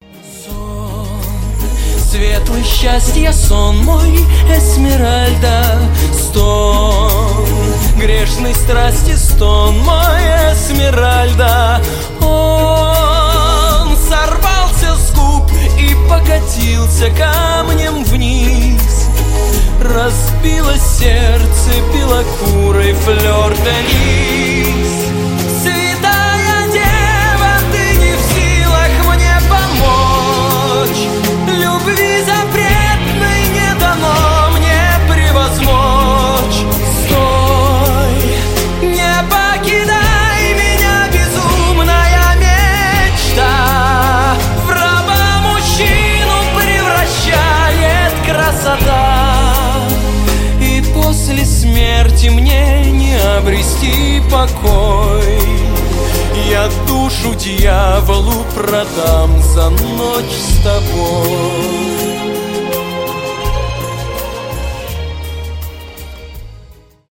мужской голос
мюзикл